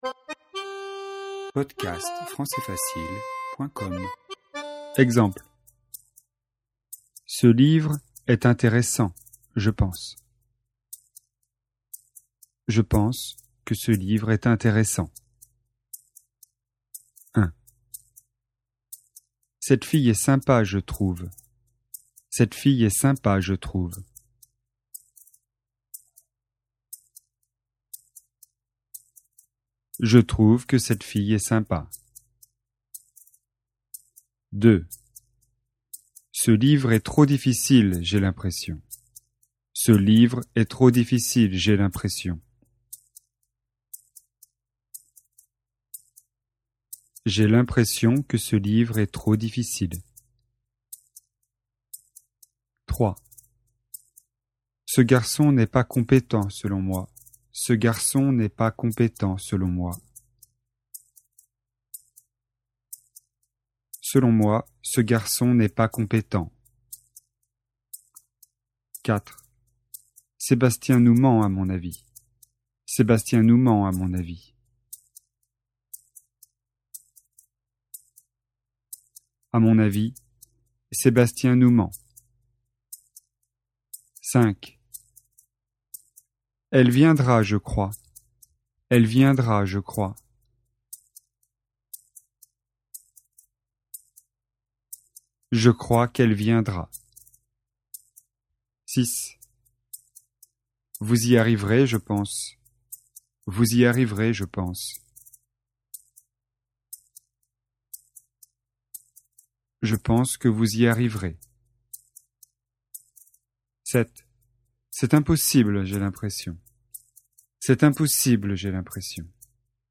Leçon de vocabulaire et exercice, niveau intermédiaire (A2), sur le thème donner son opinion.